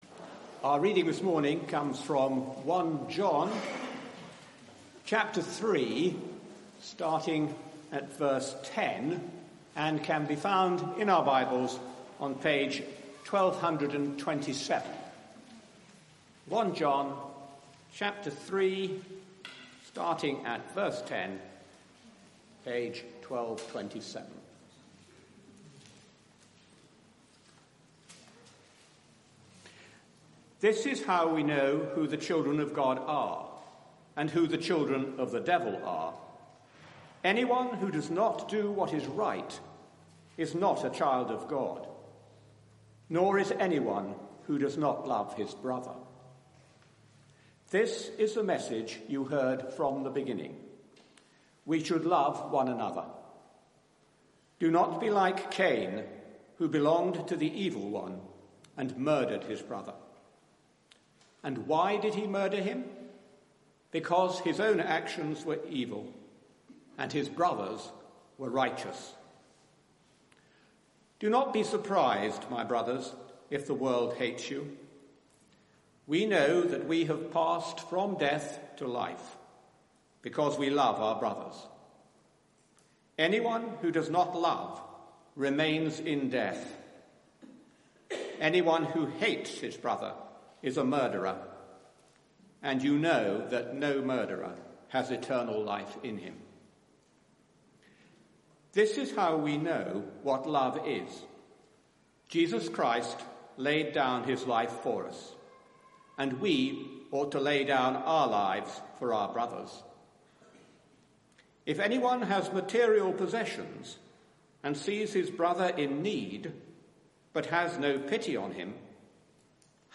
Media for 9:15am Service on Sun 09th Jun 2019 09:15
Sermon